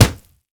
punch_grit_wet_impact_10.wav